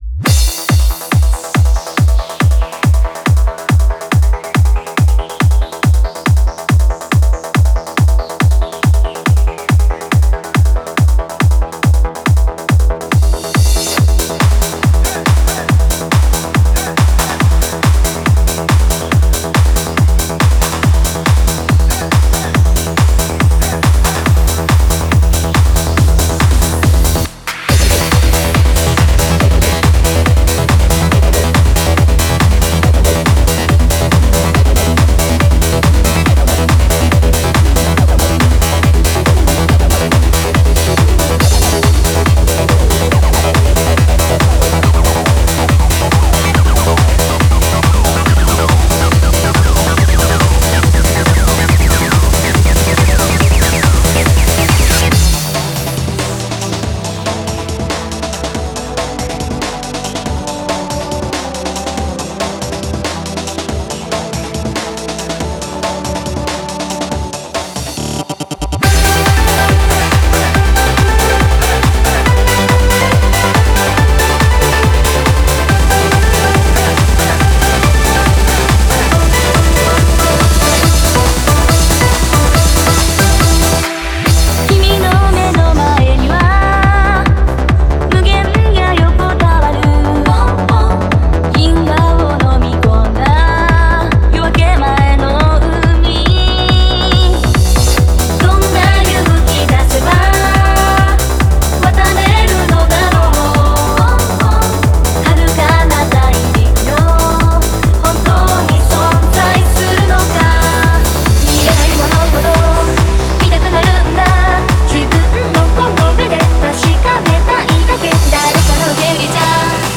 Genre : Trance / J-Pop
BPM : 140
Release Type : Bootleg / Digital